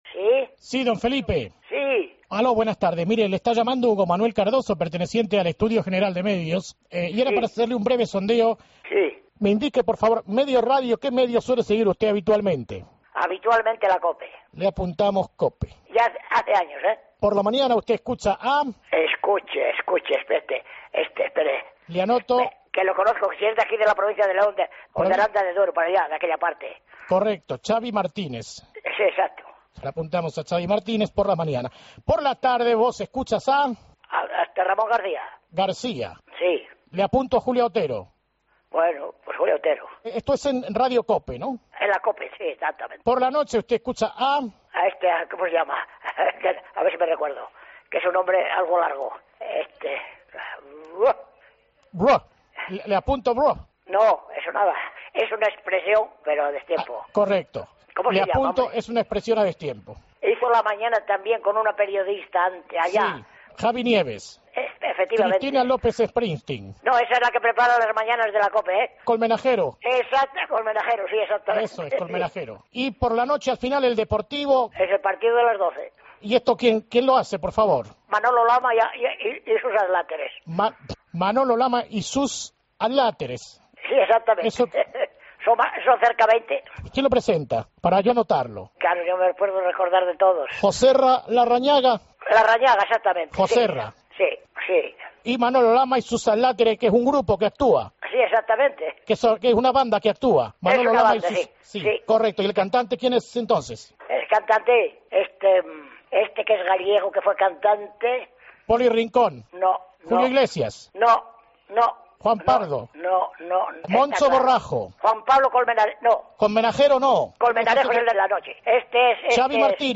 AUDIO: El Grupo RISA llama en El Partido de las 12 al oyente enfurecido para hacerle un EGM especial.